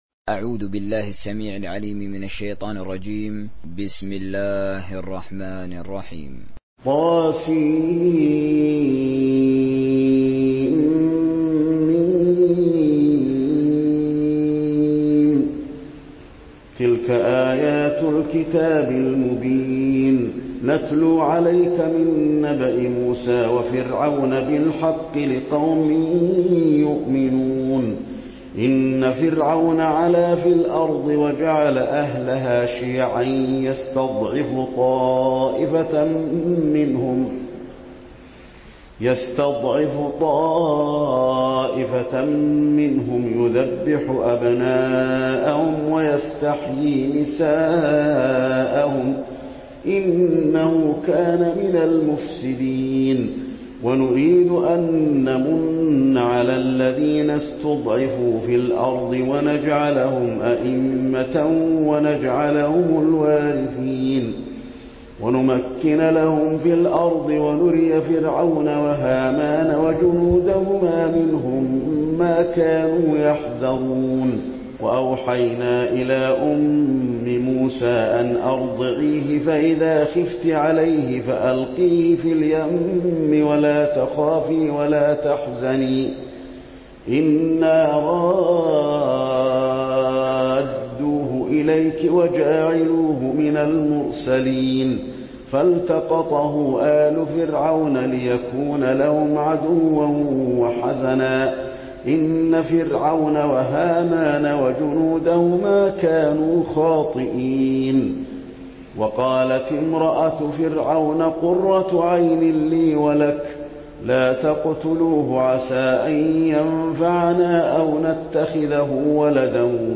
دانلود سوره القصص حسين آل الشيخ تراويح